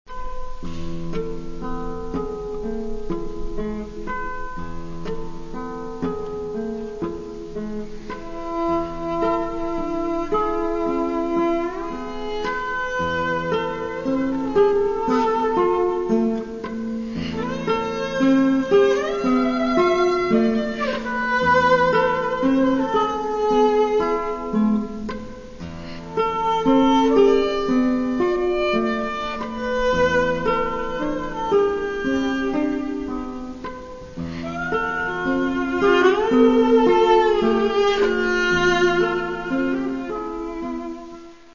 Тому що це – запис живого концерту.